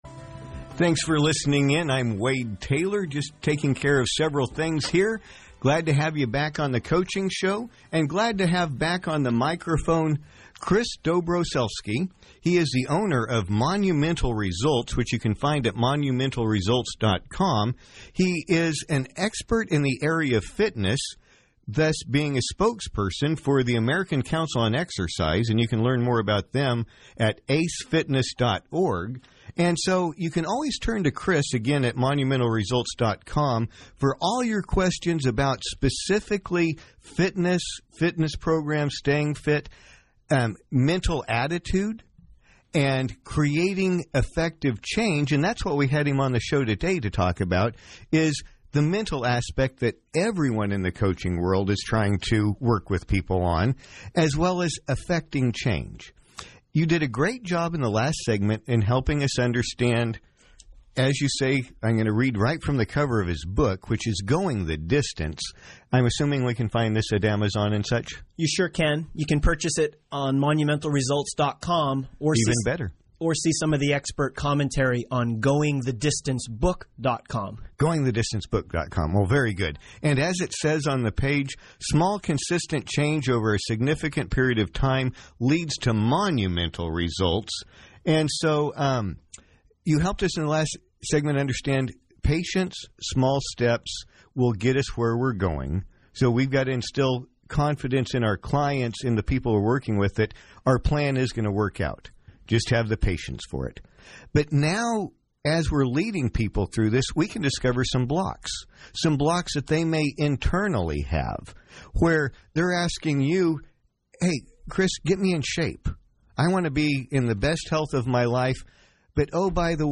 Some thoughts on a earning success from a recent radio segment I did.